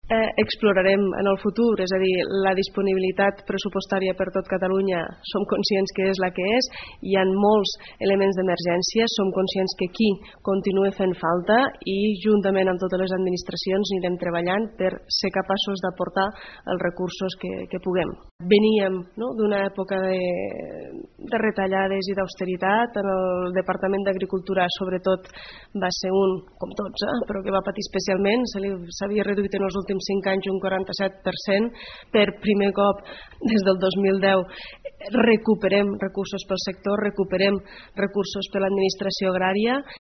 • Moment de l'entrevista de la consellera d'Agricultura. RP
Així ho ha dit en declaracions a aquesta emissora la consellera d’Agricultura, Ramaderia i Pesca, Meritxell Serret, que assegura que la nova llei d’ús del sol agrari contemplarà també facilitats per l’accés dels joves agricultors a la matèria prima, la terra.